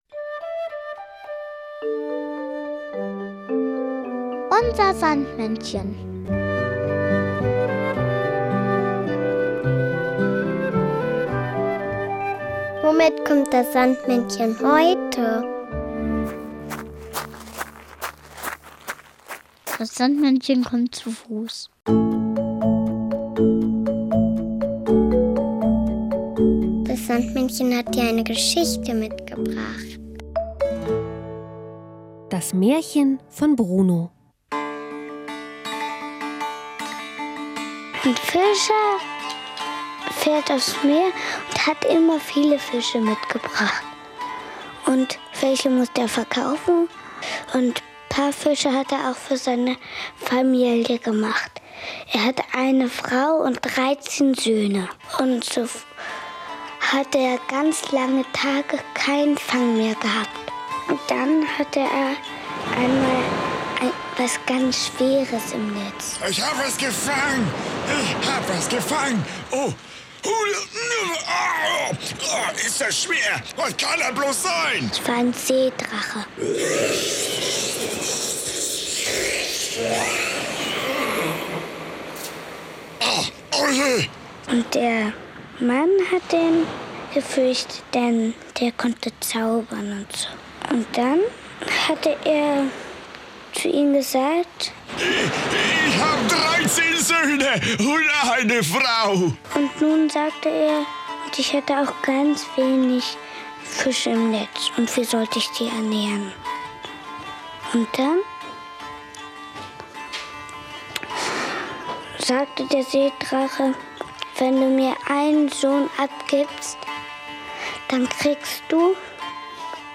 Märchen: Das Märchen von Bruno